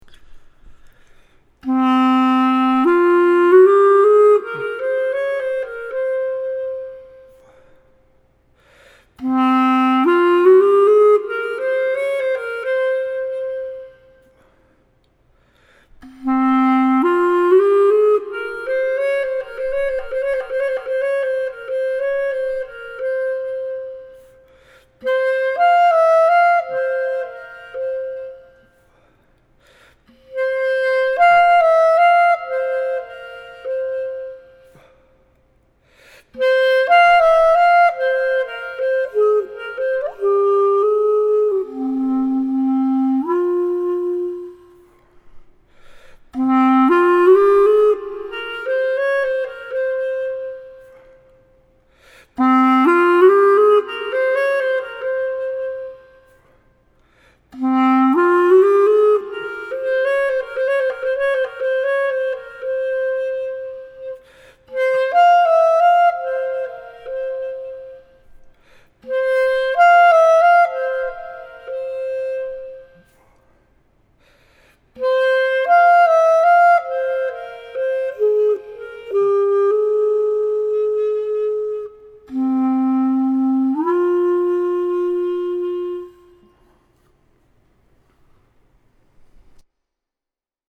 Clarinet01.mp3